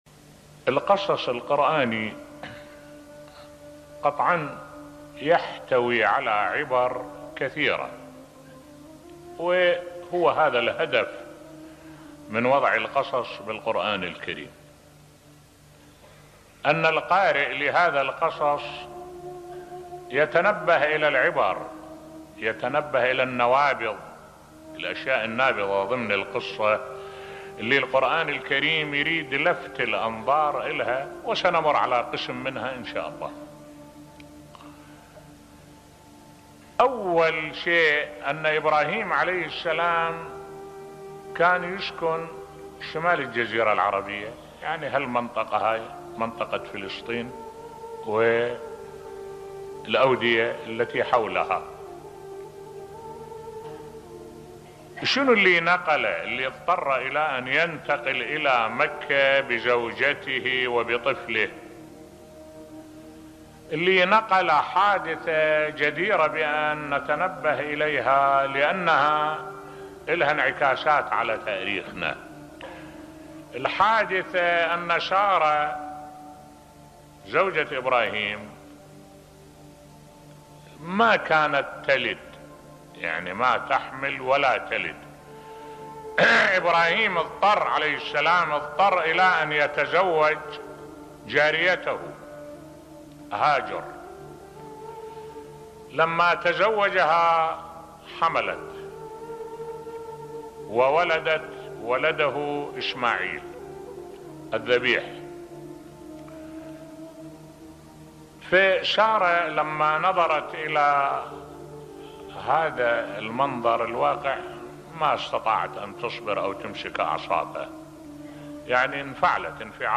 ملف صوتی حياة الام بامومتها بصوت الشيخ الدكتور أحمد الوائلي